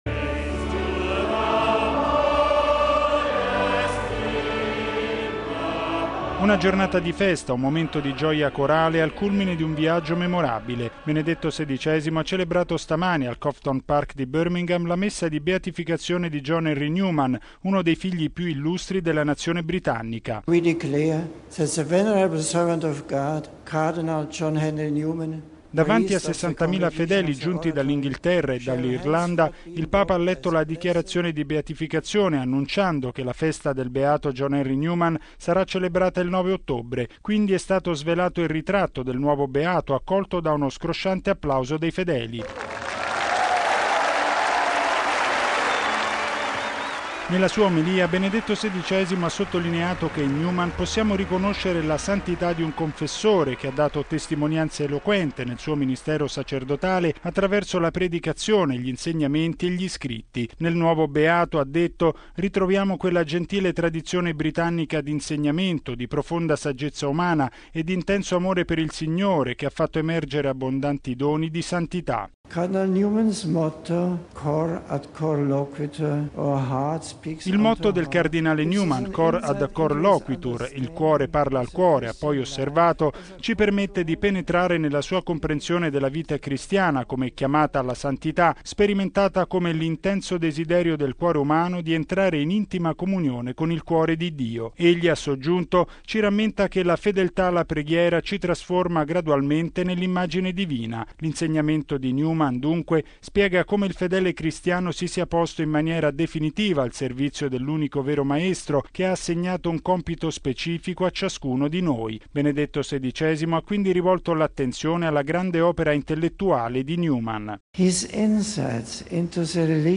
◊   Benedetto XVI ha celebrato stamani la Messa di Beatificazione del cardinale John Henry Newman, momento culminante del viaggio apostolico nel Regno Unito. Davanti a decine di migliaia di fedeli, raccolti al Cofton Park di Birmingham, il Papa ha rinnovato l’appello del nuovo Beato per un laicato intelligente e non arrogante, che sappia ciò in cui crede.